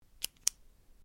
На этой странице собраны звуки пишущей ручки: от легкого постукивания по бумаге до равномерного скольжения стержня.
Ручка бик кнопку отжали